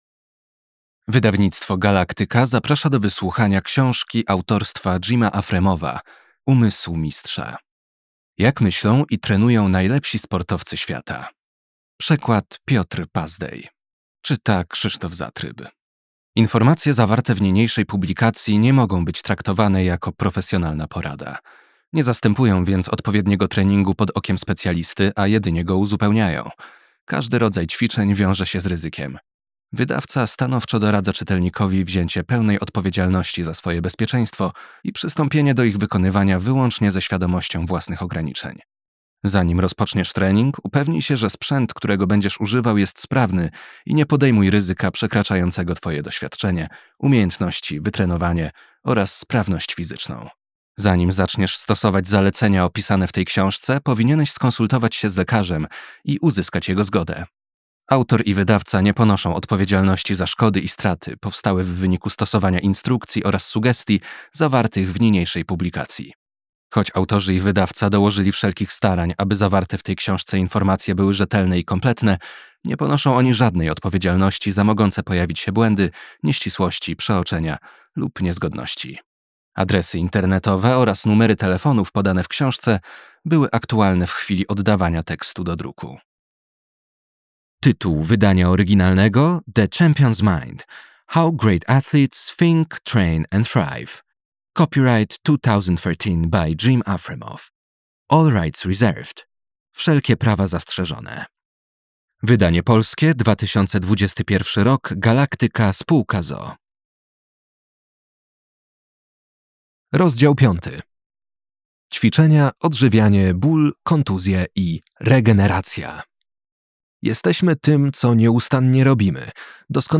fragment książki: